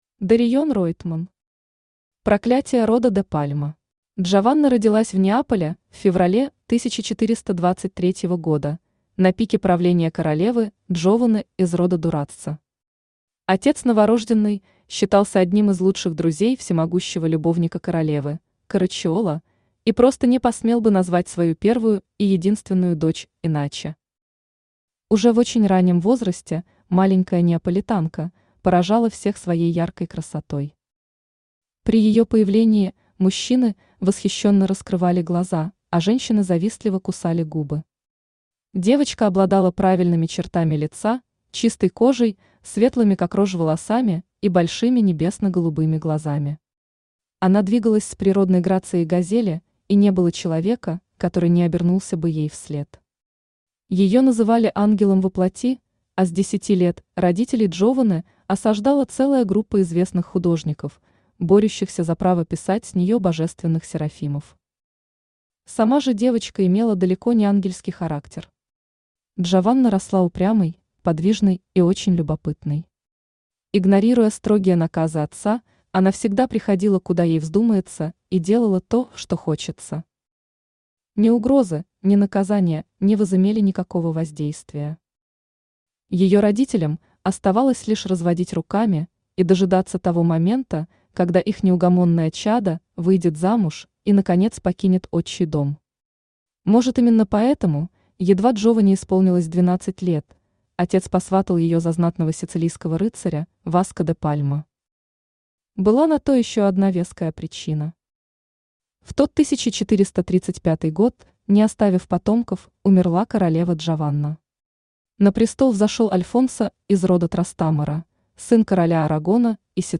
Аудиокнига Проклятие рода де Пальма | Библиотека аудиокниг
Aудиокнига Проклятие рода де Пальма Автор Дариен Ройтман Читает аудиокнигу Авточтец ЛитРес.